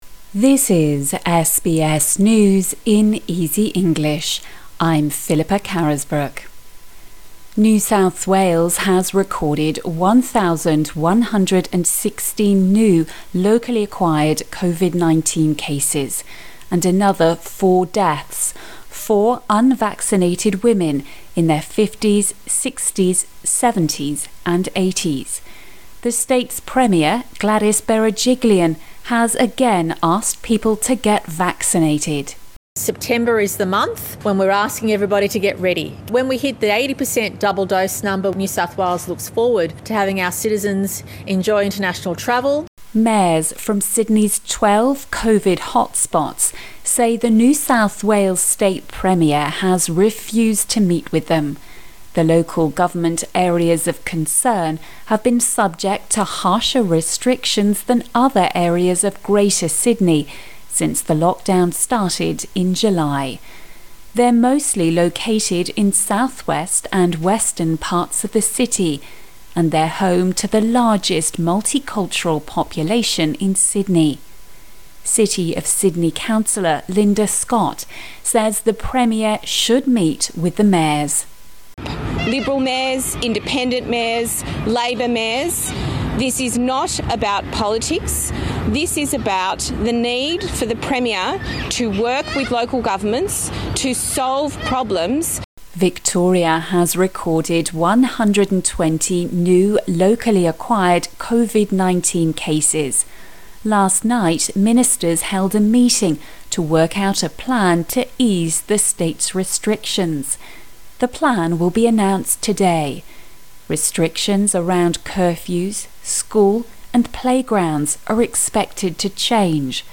A daily 5 minute news wrap for English learners.